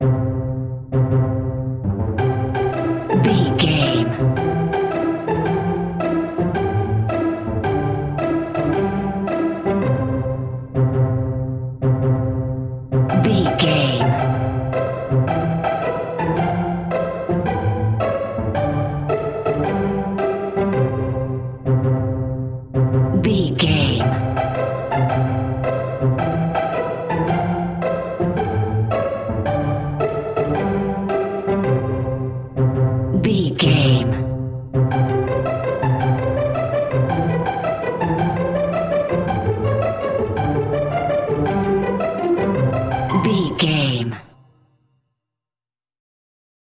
Uplifting
Ionian/Major
nursery rhymes
childrens music